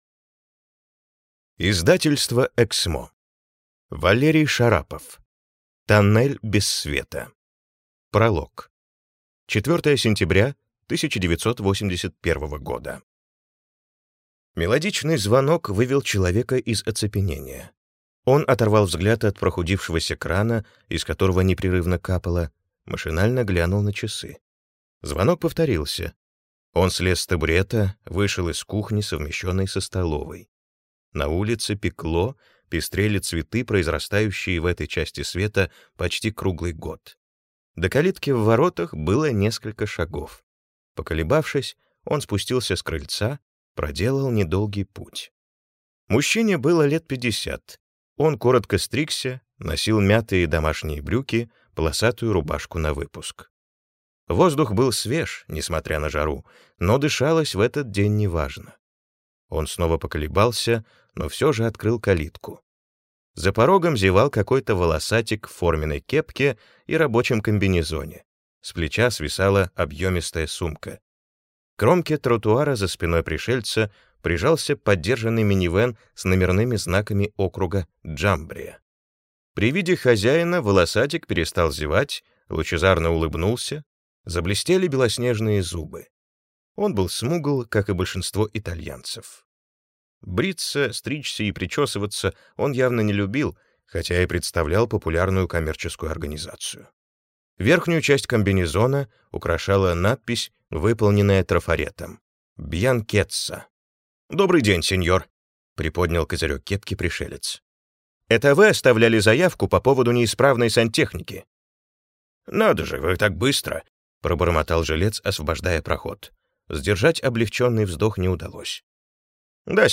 Аудиокнига Тоннель без света | Библиотека аудиокниг